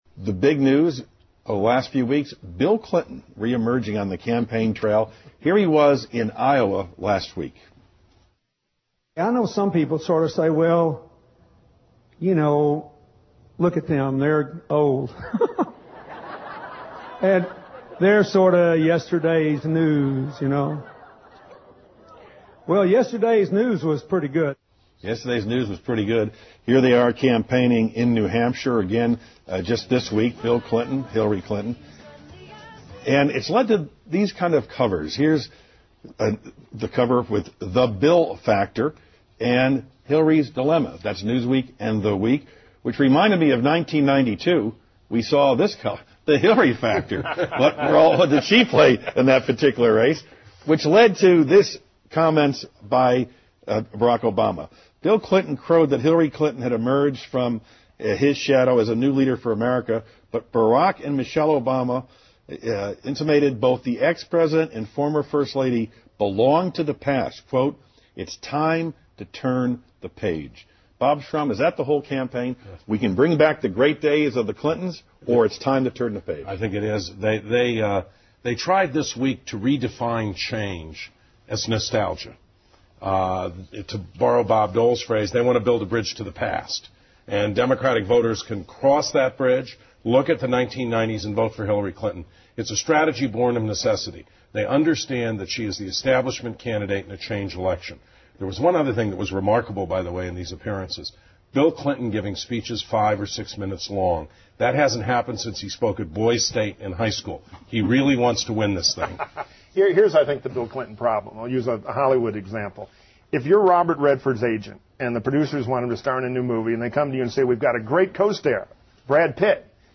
访谈录 Interview 2007-08-03&08-05, 比尔·希拉里现象 听力文件下载—在线英语听力室